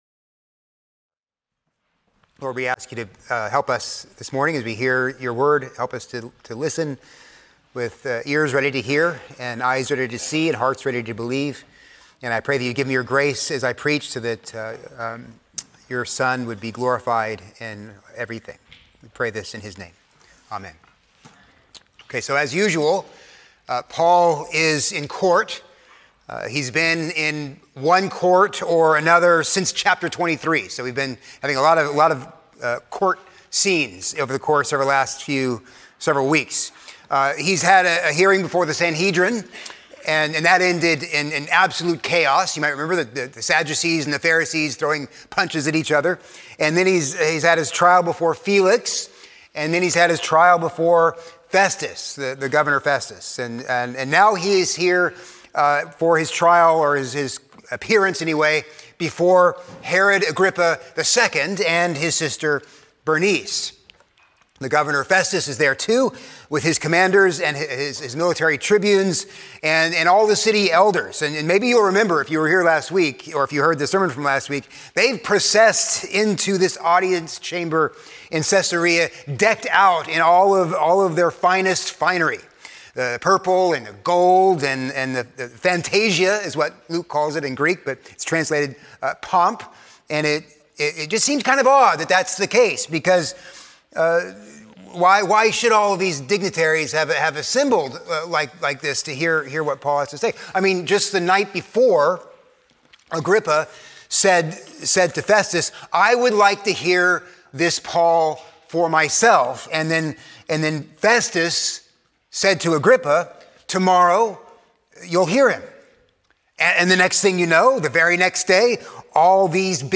A sermon on Acts 26:1-11